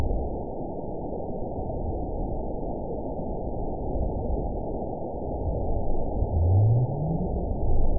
event 912527 date 03/28/22 time 22:03:41 GMT (3 years, 1 month ago) score 9.67 location TSS-AB04 detected by nrw target species NRW annotations +NRW Spectrogram: Frequency (kHz) vs. Time (s) audio not available .wav